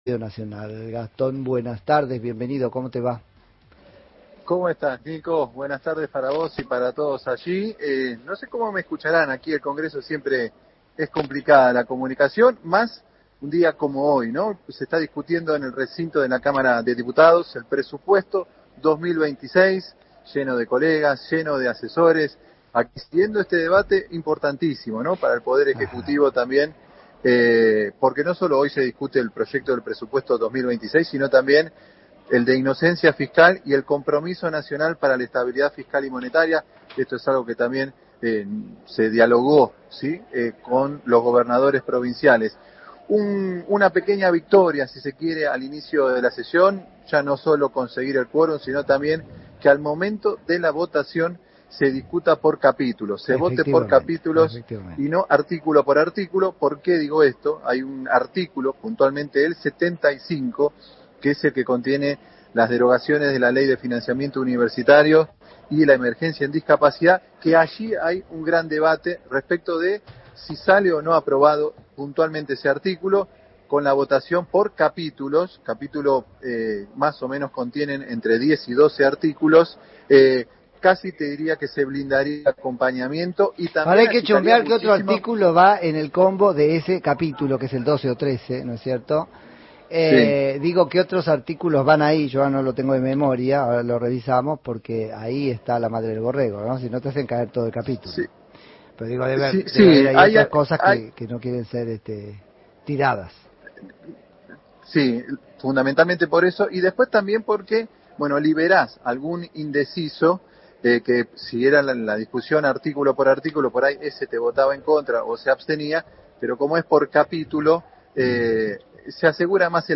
entrevista Se viene otra edición de la Feria Internacional del Libro de Buenos Aires